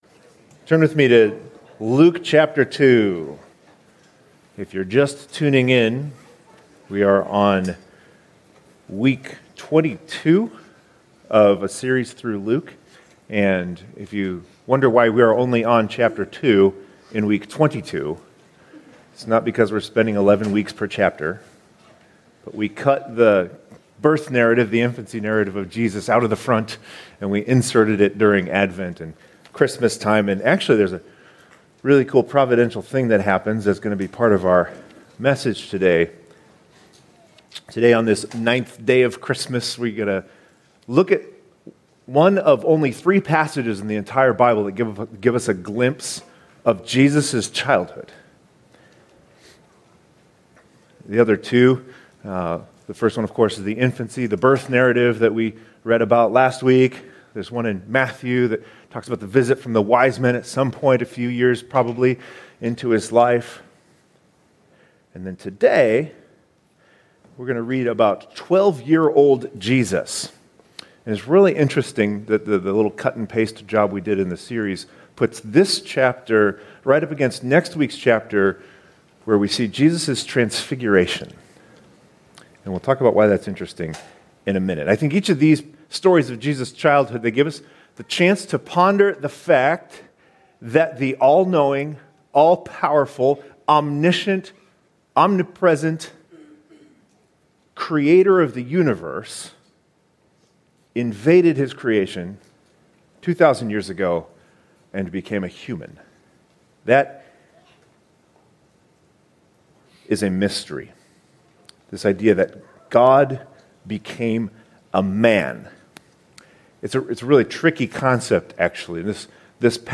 2022 Stay up to date with “ Stonebrook Church Sermons Podcast ”